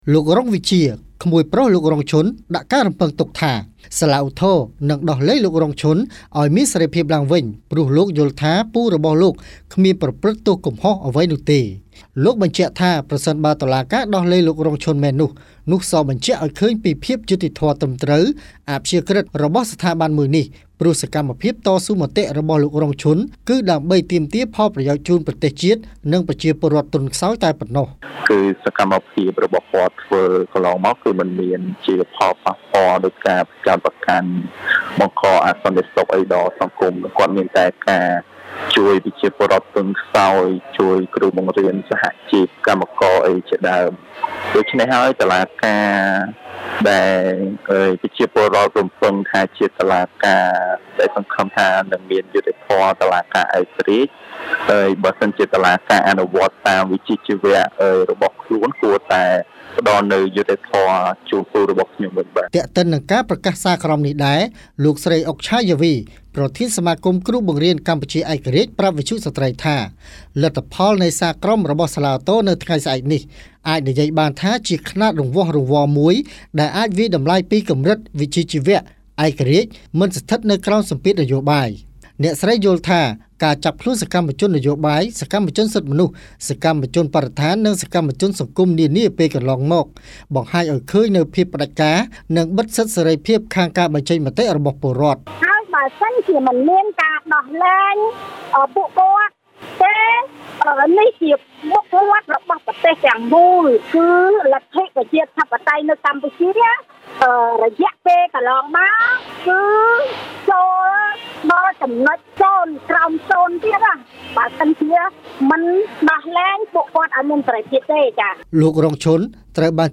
ផ្តល់កិច្ចសម្ភាសន៍នៅមុខសាលាដំបូងរាជធានីភ្នំពេញ។